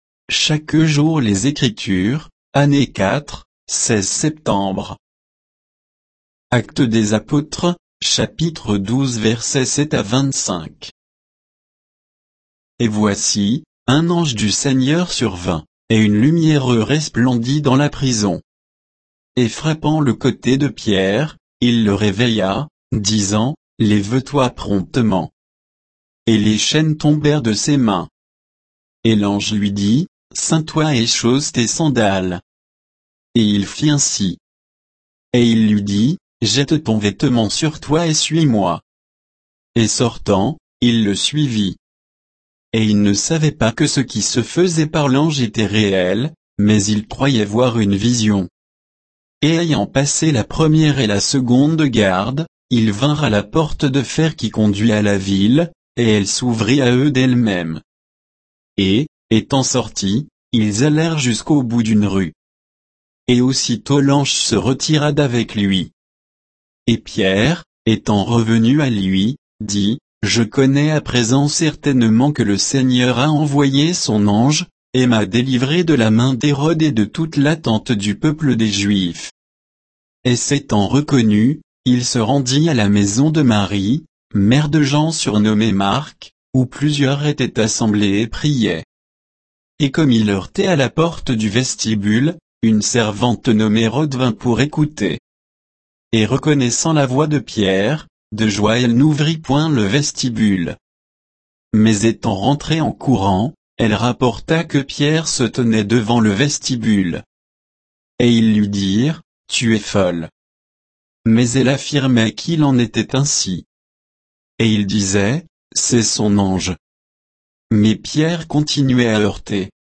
Méditation quoditienne de Chaque jour les Écritures sur Actes 12, 7 à 25